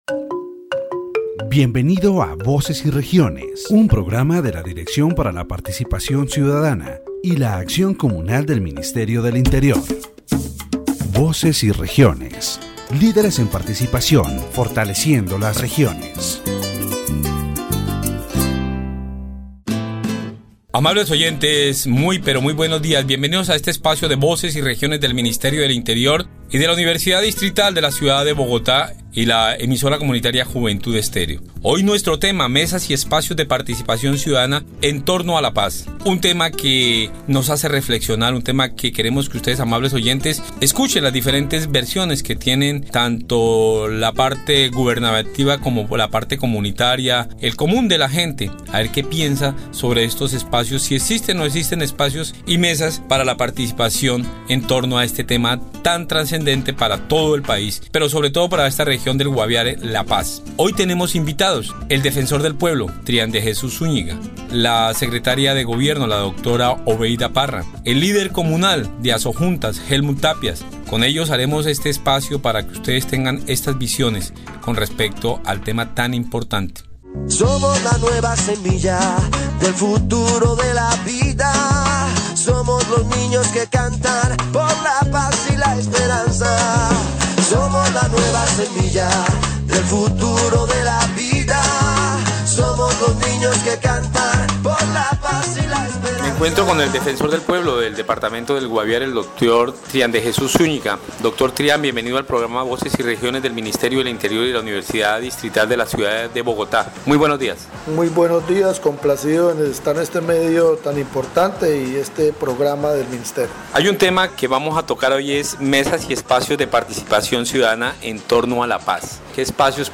In this section of the Voces y Regiones program, the topic of Peace Dialogues in Guaviare is addressed. The interviewee highlights the alarming reality of child recruitment in the armed conflict, emphasizing how the lack of educational opportunities and access to technology makes young people vulnerable targets for armed groups. Additionally, the discussion underscores the need to establish dialogue tables between the national government and local communities, fostering spaces for negotiation and peacebuilding.